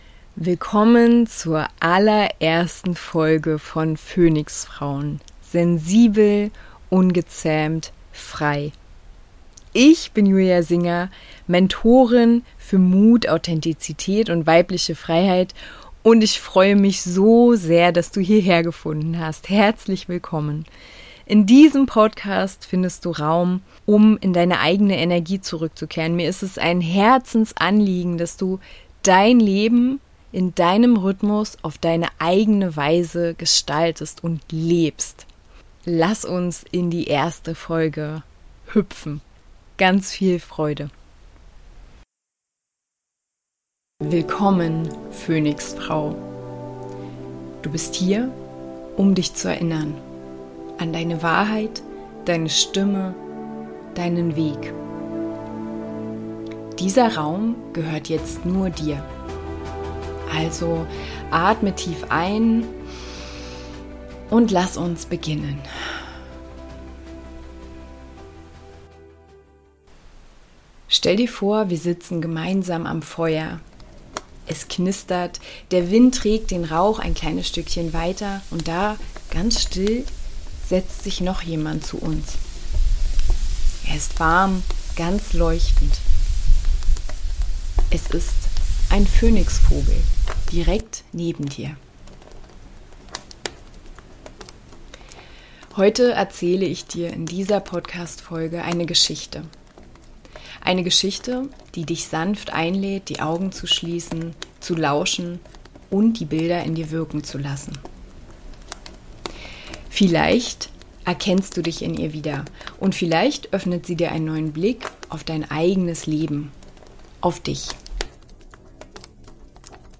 02: Der Ruf des Phönix- Vogels: Meditative Kurzgeschichte zurück zu Dir ~ PHÖNIX-FRAUEN: Sensibel. Ungezähmt. Frei. Podcast